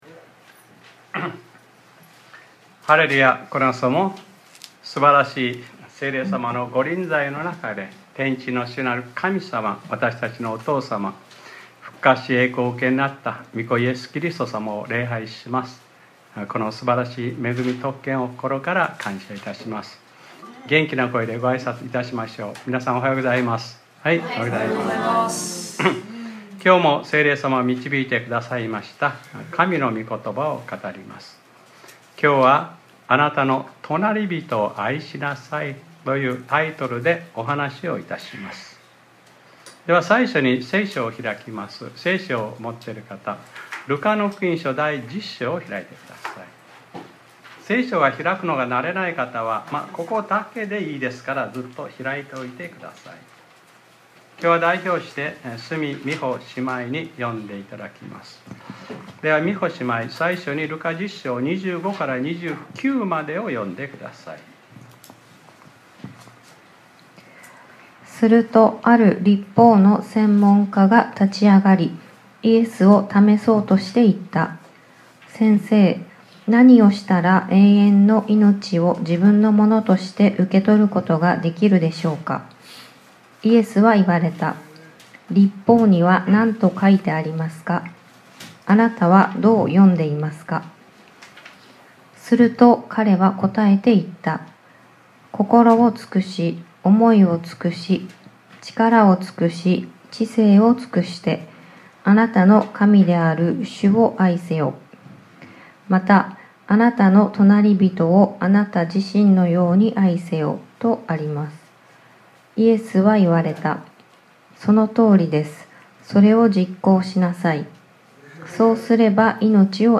2025年02月02日（日）礼拝説教『 あなたの隣人を愛しなさい 』 | クライストチャーチ久留米教会